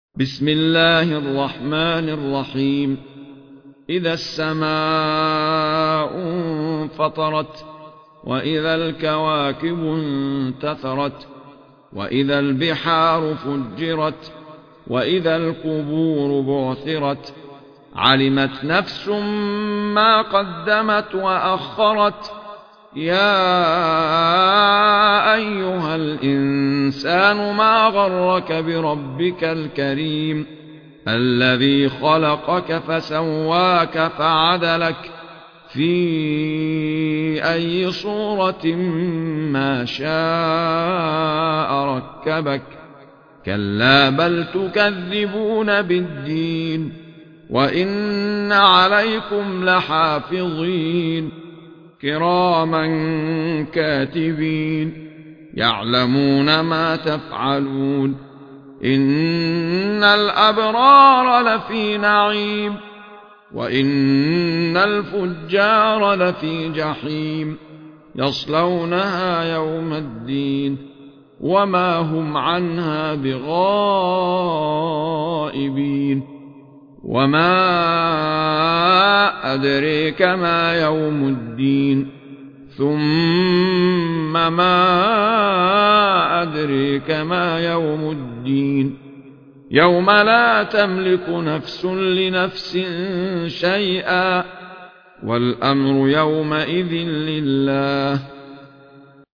مصاحف - أحمد عيسى المعصراوي
المصحف المرتل - شعبة عن عاصم بن أبي النجود